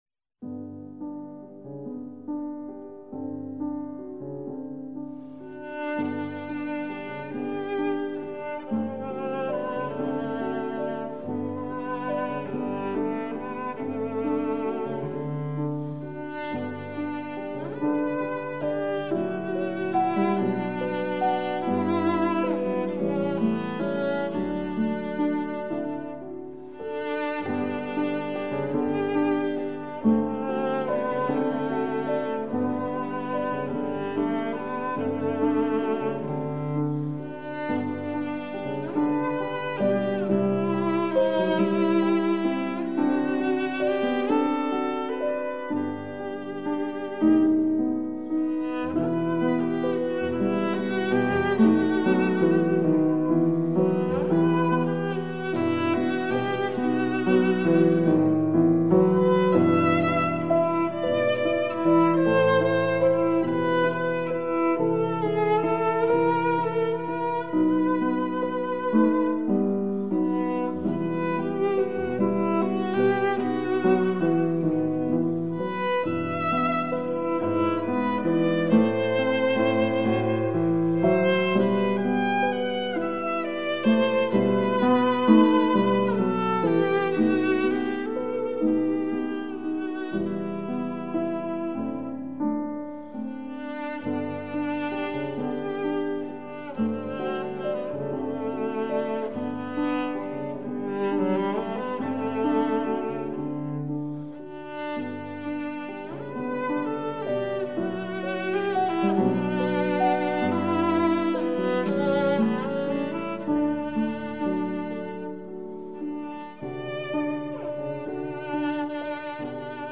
The pieces are inthe Romantic style.